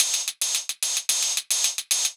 Index of /musicradar/ultimate-hihat-samples/110bpm
UHH_ElectroHatC_110-04.wav